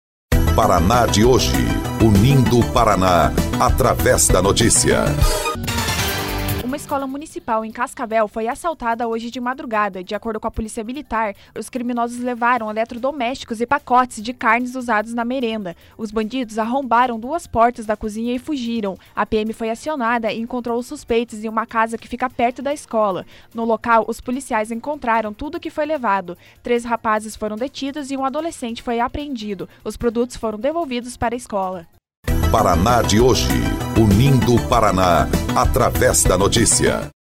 19.12 – BOLETIM – Escola Municipal é assaltada na madrugada, em Cascavel